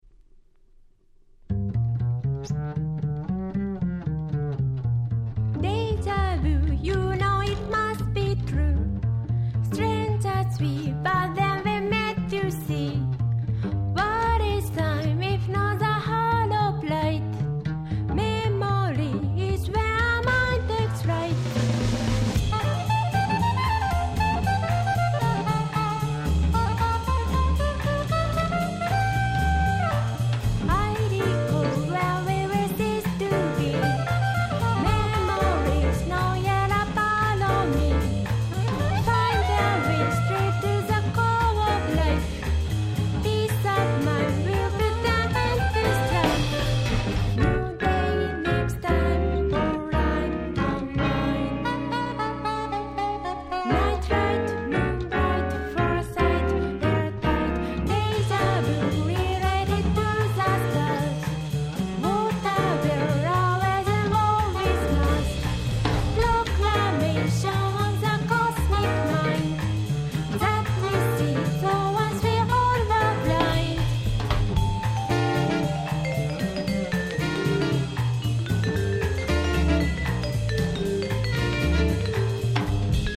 Bossa調からClub Jazzまで本当に良曲揃い！！